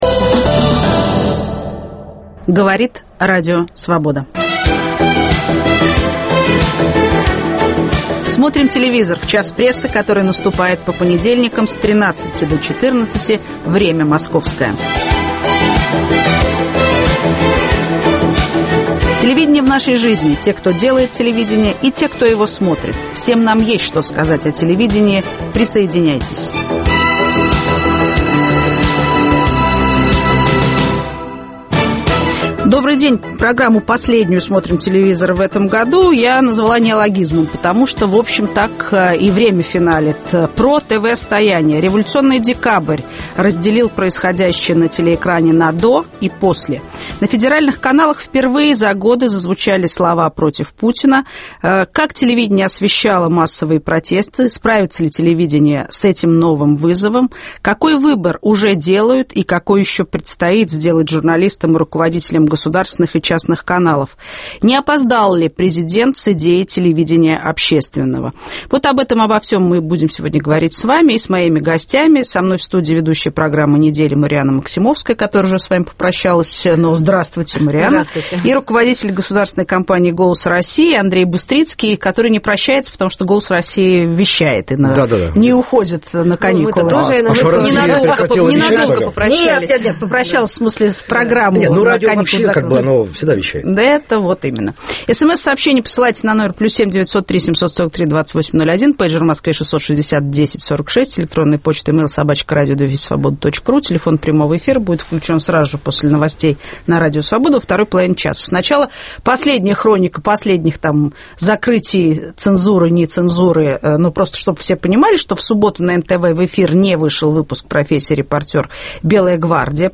В студии - ведущая программы "Неделя" Марианна Максимовская и руководитель государственной компании "Голос России" Андрей Быстрицкий.